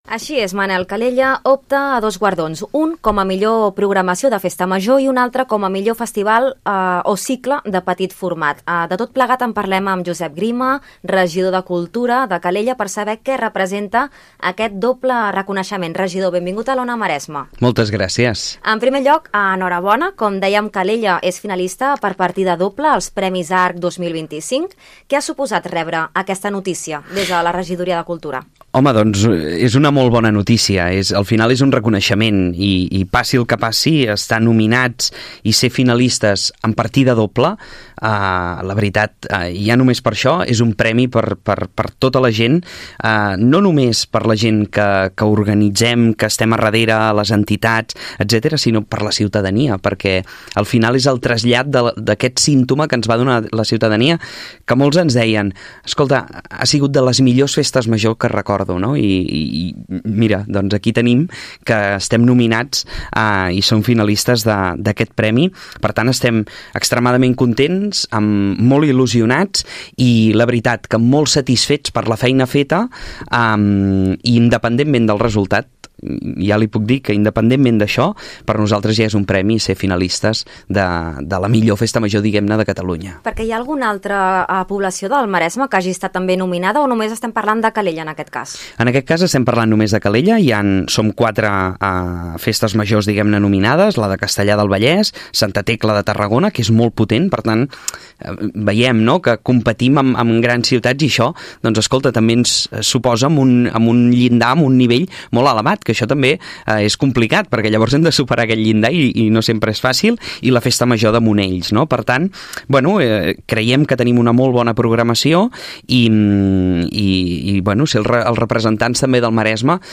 El regidor de Cultura, Josep Grima, ha celebrat a Ona Maresme la doble nominació de Calella als Premis ARC 2025, que reconeixen la millor programació musical de Catalunya. La Festa Major de la Minerva competeix en la categoria de Festes Majors, i el Festival Nits d’Estiu (NEC) ho fa dins dels cicles de petit format. Grima ha destacat que aquestes nominacions són un reconeixement al treball de l’equip i al nivell cultural de la ciutat.